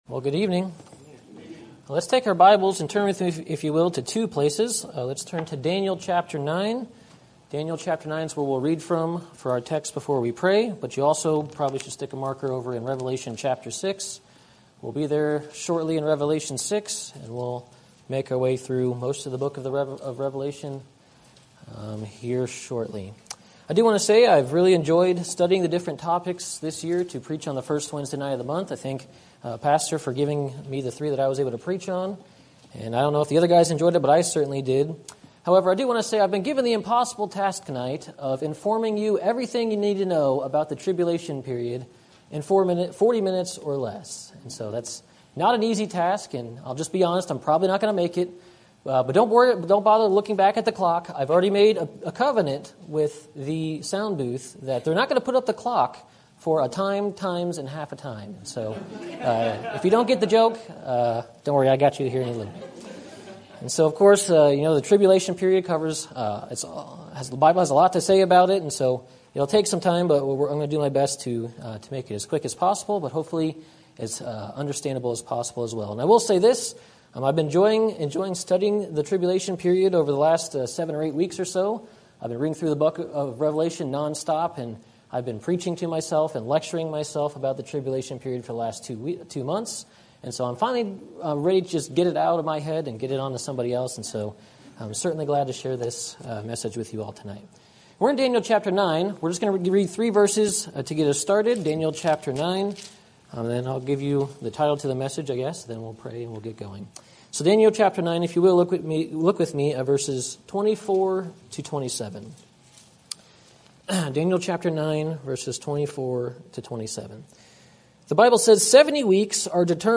Sermon Topic: General Sermon Type: Service Sermon Audio: Sermon download: Download (23.81 MB) Sermon Tags: Daniel Revelation Tribulation Doctrine